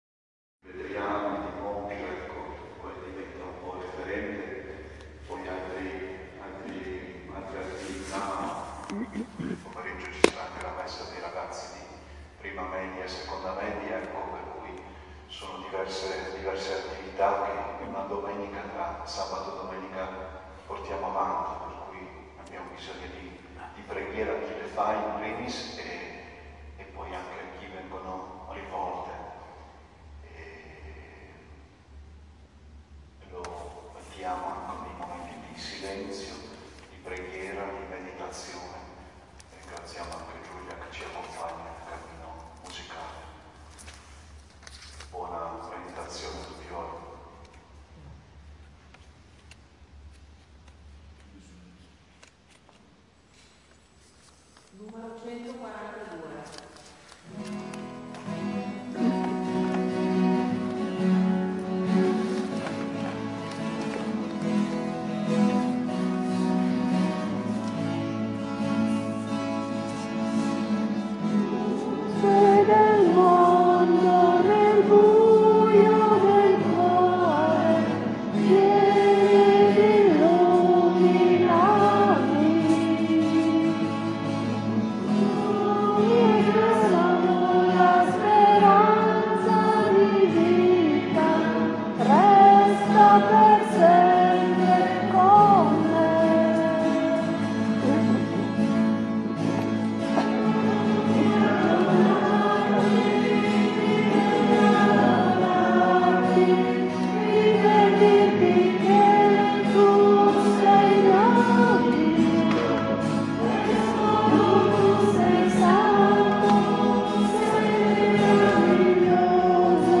Registrazione audio del Ritiro di Avvento 2022.